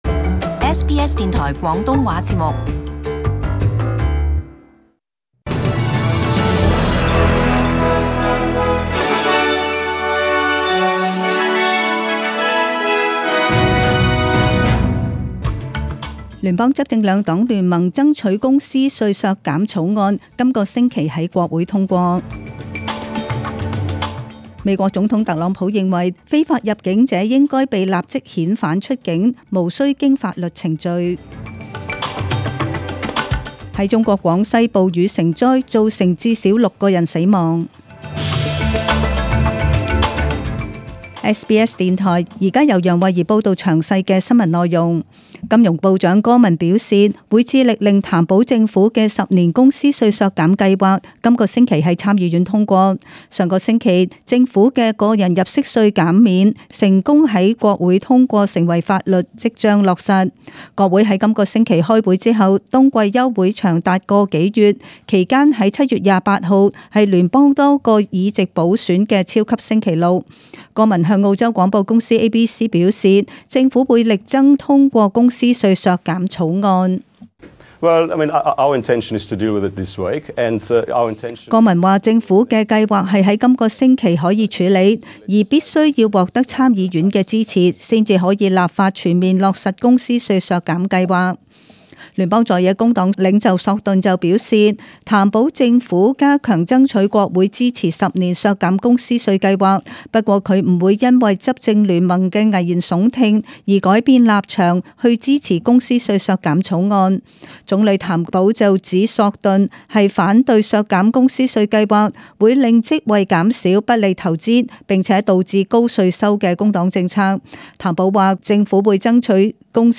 SBS中文新闻 （六月廿五日）
请收听本台为大家准备的详尽早晨新闻。